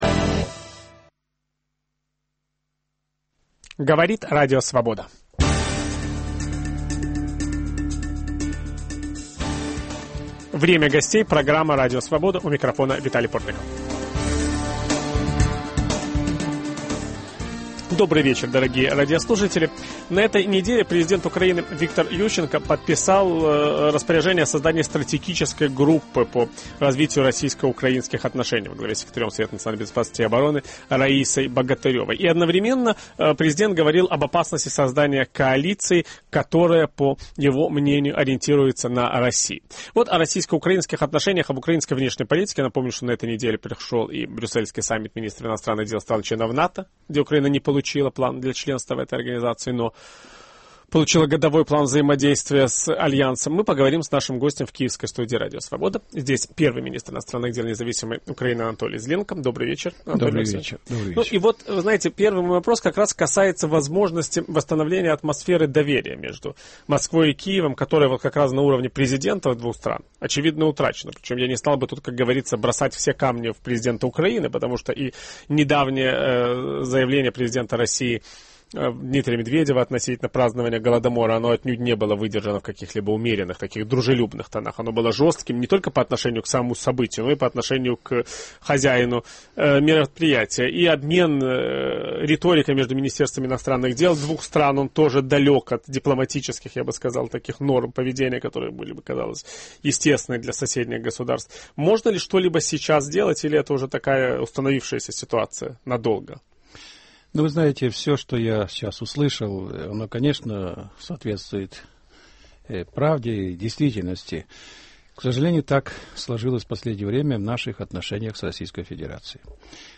Возможно ли восстановление доверия в российско-украинских отношениях? С Виталием Портниковым беседует первый министр иностранных дел независимой Украины Анатолий Зленко